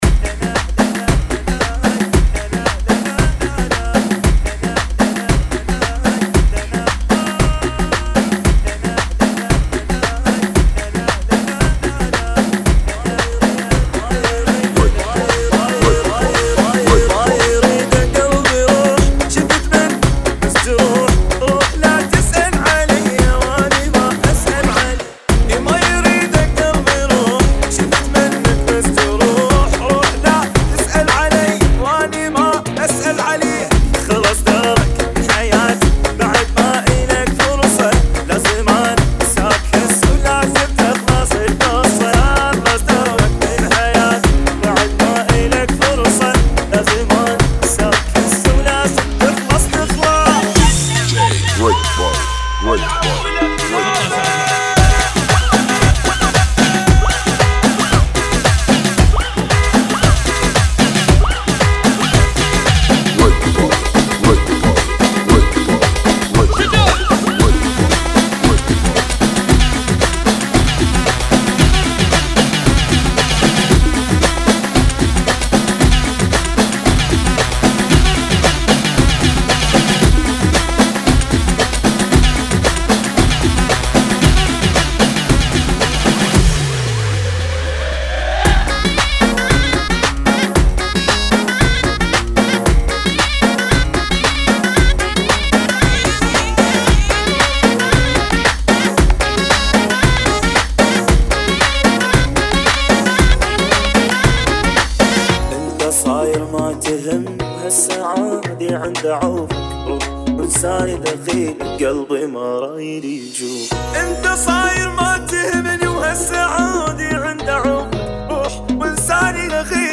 [ 114 bpm ]